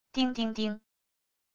叮~叮~叮~wav音频